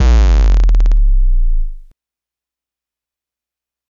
8 bit with sub.wav